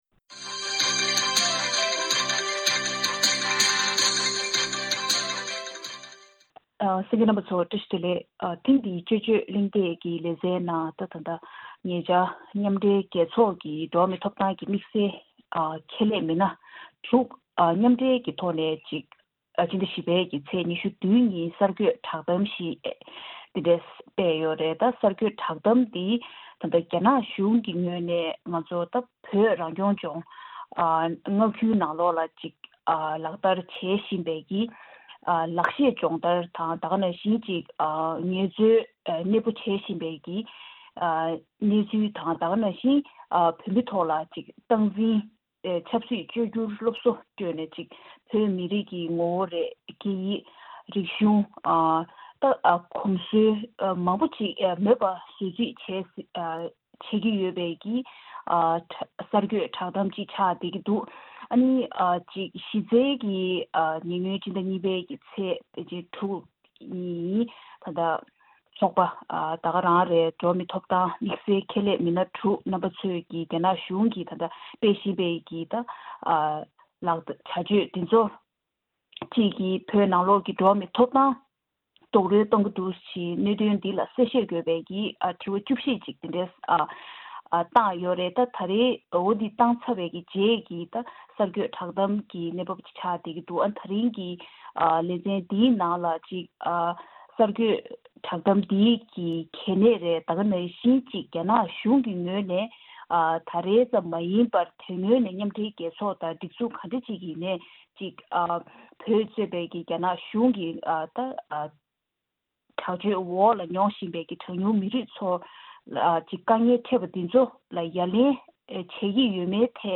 དཔྱད་གཞིའི་གླེང་མོལ